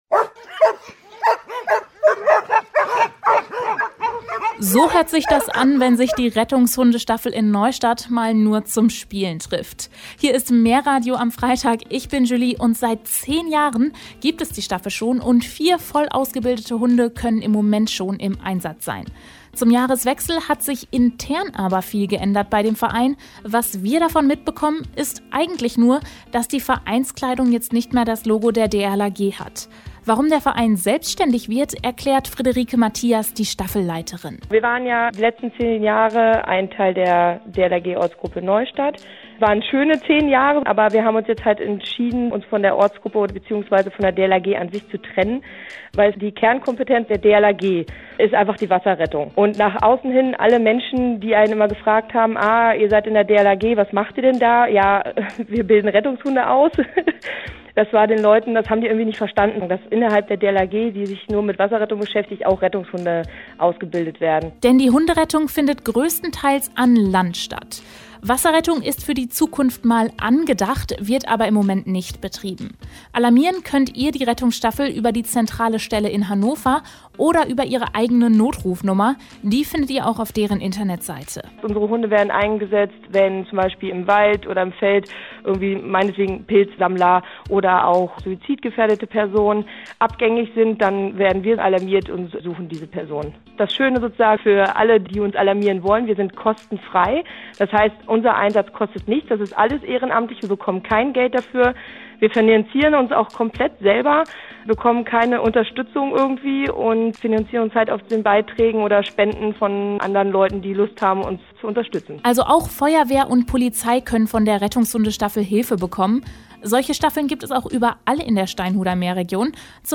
In den letzten Tagen waren wir gleich mehrfach im Radio zu hören.